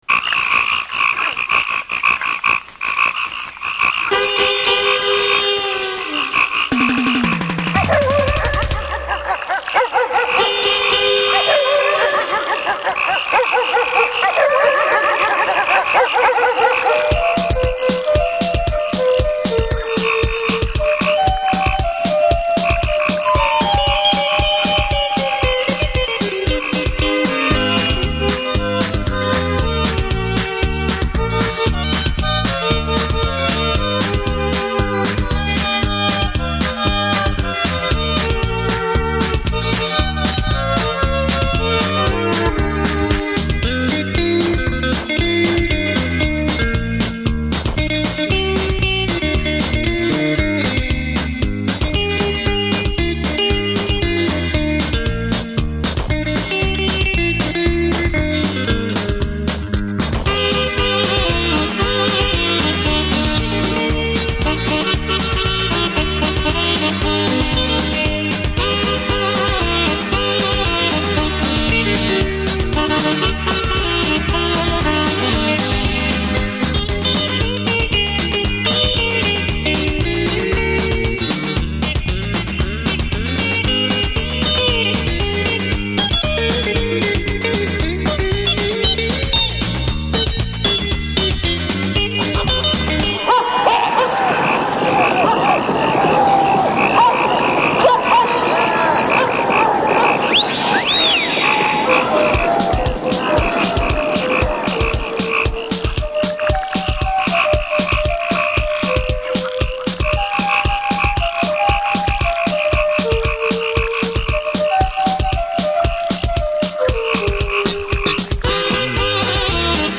本专辑是一张好听的效果音乐唱片，大部分曲目
选自电影音乐，其中插入逼真的效果录音，如：
人的呐喊声，马的叫声，枪炮与爆炸声，战机的
轰鸣声等，与主题音乐交织在一起，妙趣横生，